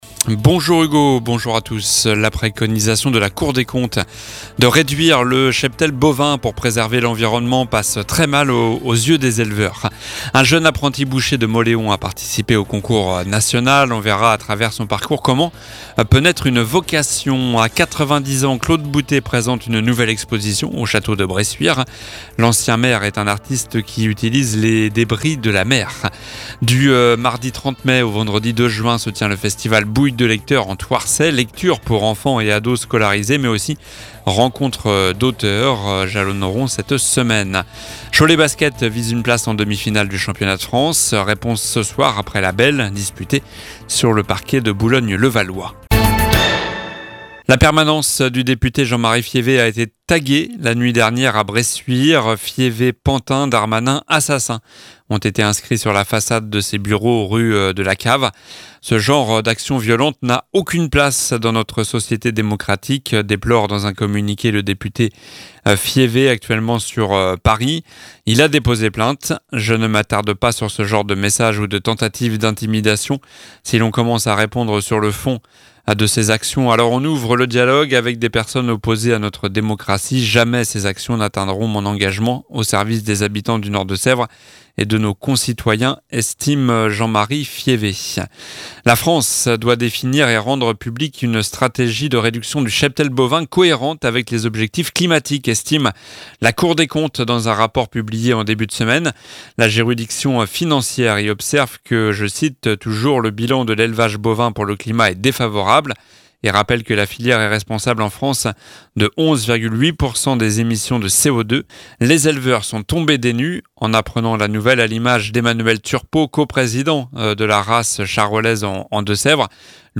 JOURNAL DU JEUDI 25 MAI (MIDI)